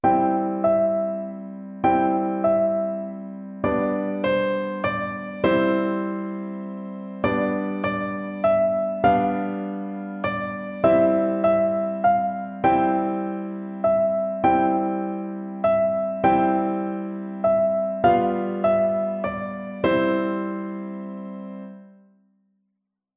KlavierkursNr023KuckuckKuckuck.mp3